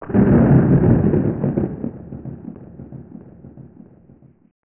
Thunder and Lightning:
thunder.
THUNDER.WAV